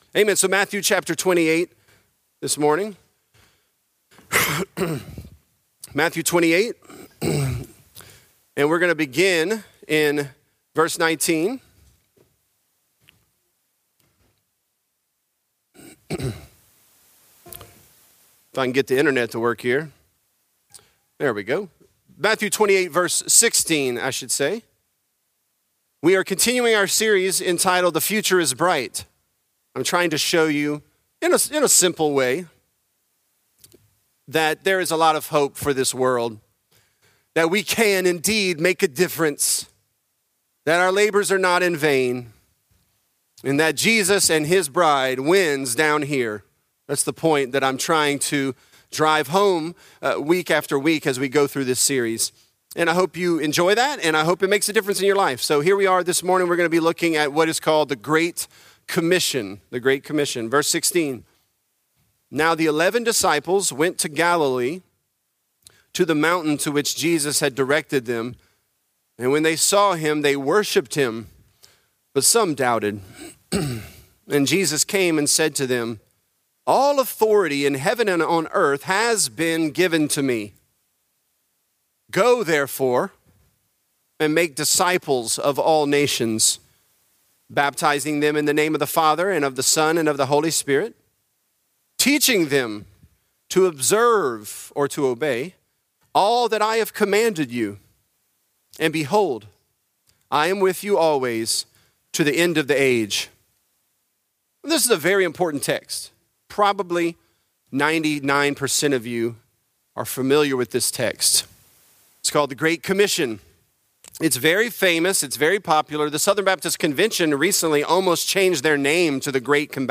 This is a part of our sermon series, "The Future Is Bright."